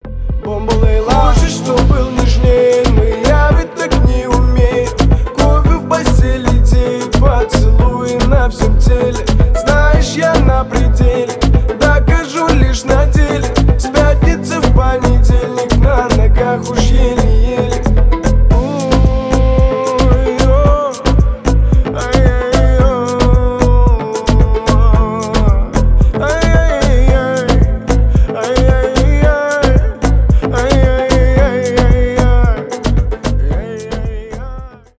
танцевальные
поп